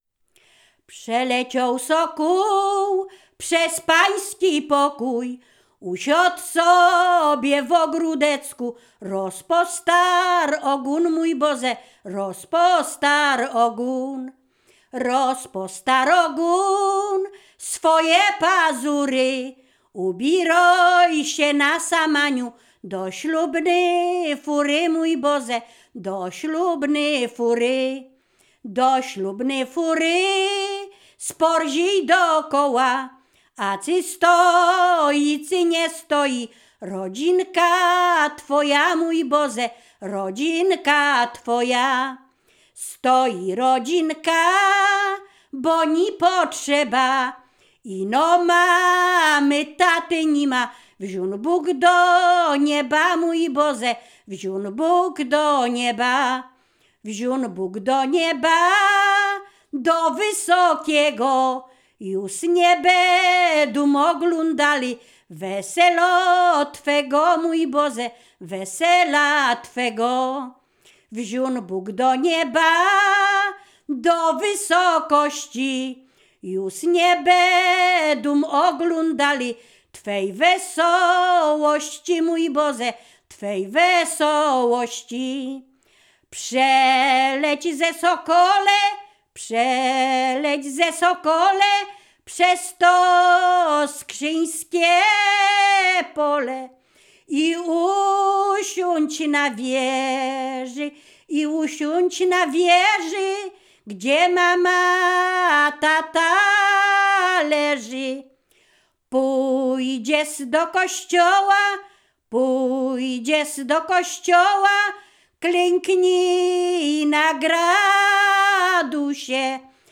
Ziemia Radomska
miłosne weselne wesele